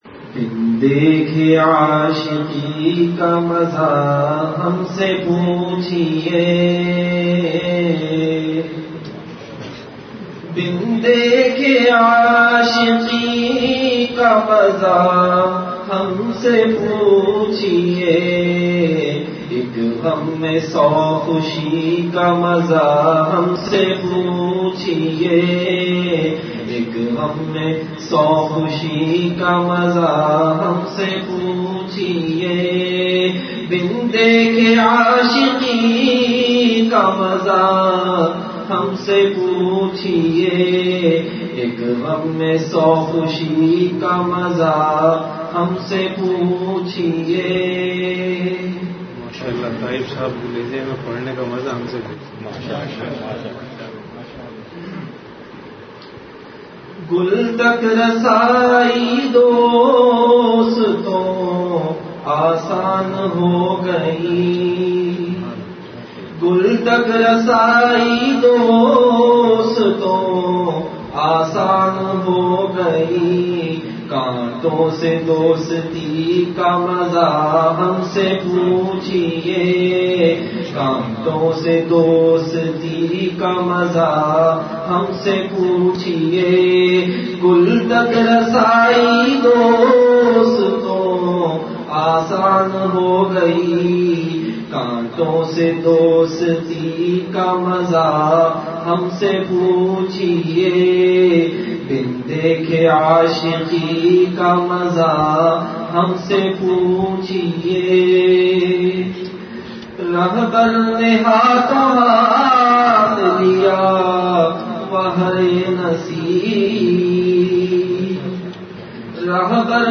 Majlis-e-Zikr · Home Eeman